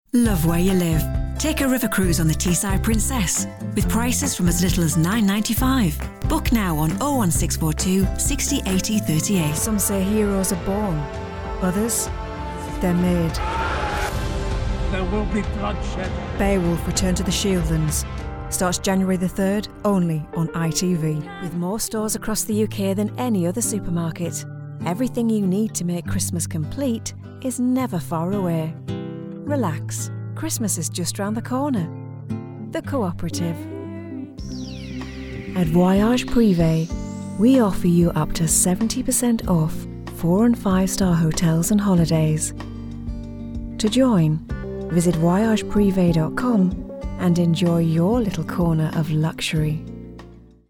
Teesside
Range 20s - 50s
Soft & engaging North East voice with experience in radio & TV ads, continuity, and promos.